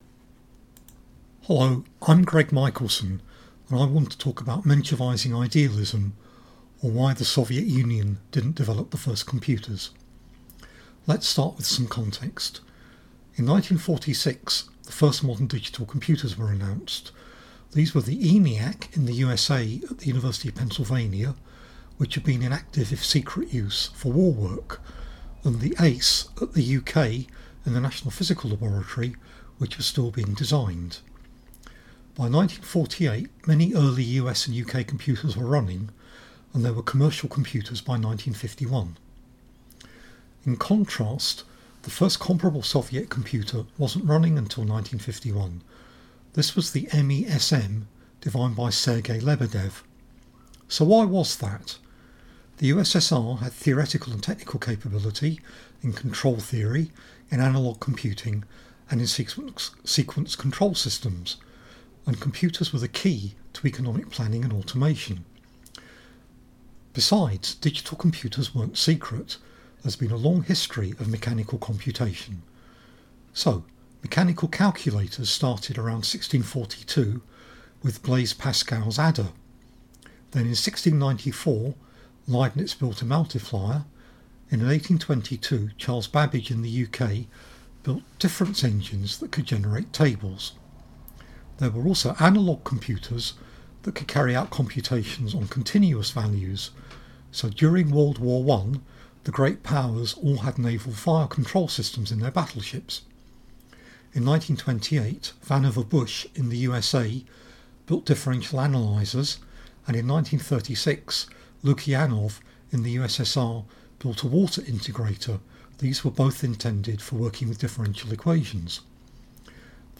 Menshevizing idealism, or why the Soviet Union didn't build the first computers Menshevizing idealism, or why the Soviet Union didn't build the first computers These are the more or less consistent slides and podcast for the Oxford Communist Corresponding Society presentation on 3/12/2020.